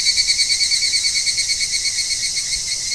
ヒグラシの声(65KB wave)
higurasi.wav